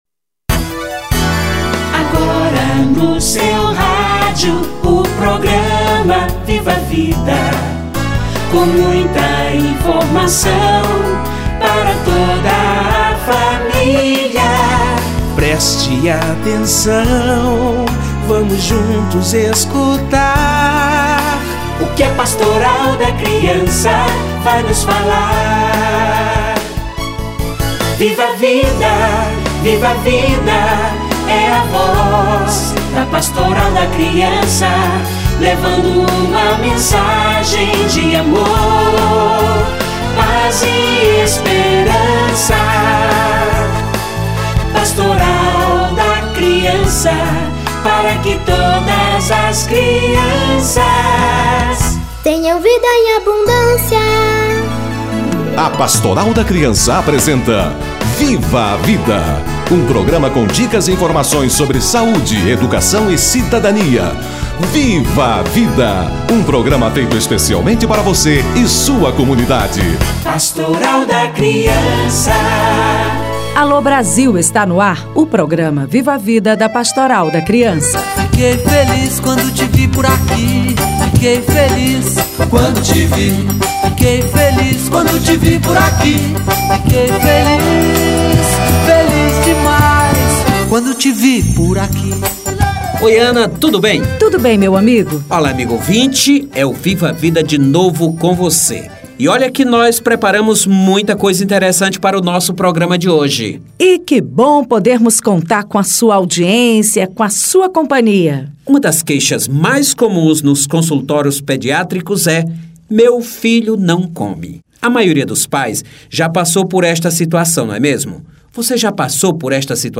Meu filho não come - Entrevista